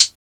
Closed Hats